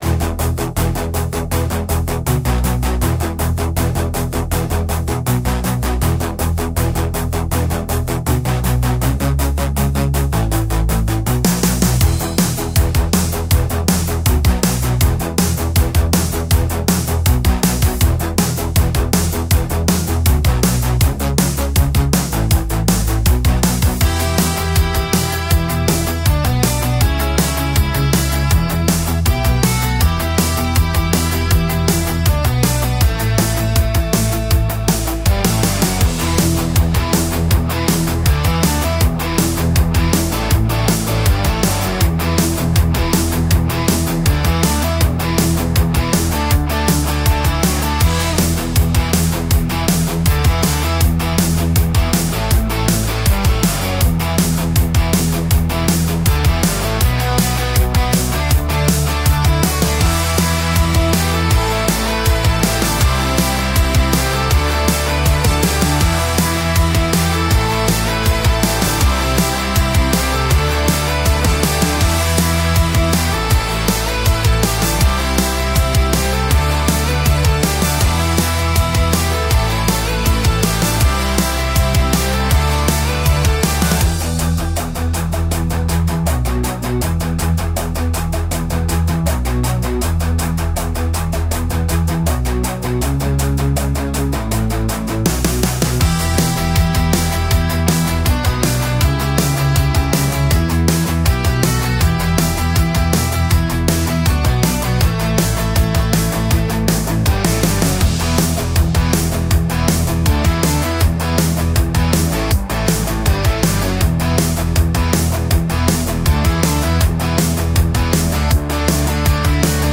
Инструментальная музыка